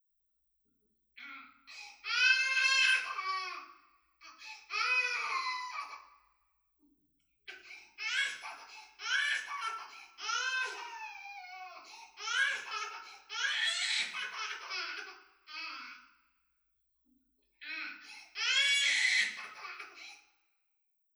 赤ちゃんの泣き声で
響きを比べました。
(5.4×9.1m、高さ2.5mの部屋)
泣き声が響かず、
うるさく感じない
sound_rockwool.wav